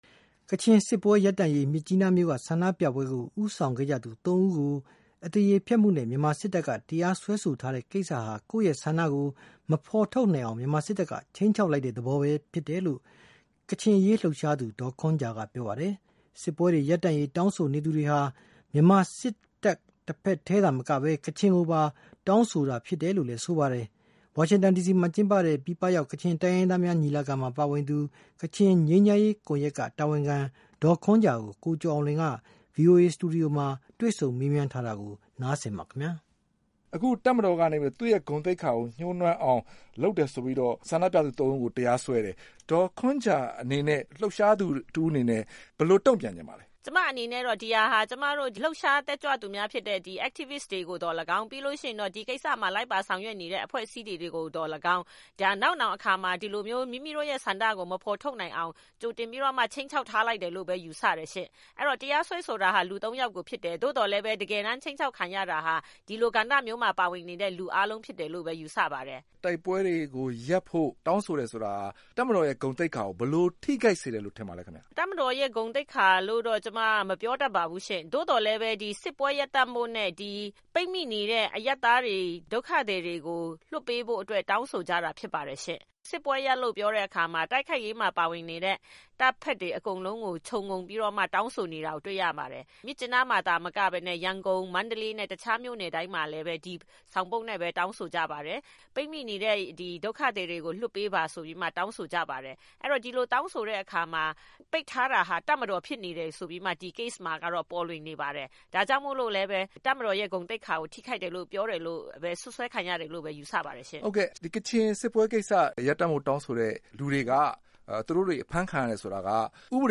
ဗွီအိုအေ စတူဒီယိုမှာ တွေ့ဆုံမေးမြန်းထားတာပါ။